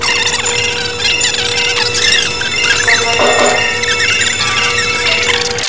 This sound sample or audio image comprises 45.62 secs of digitised speech, spoken by humans.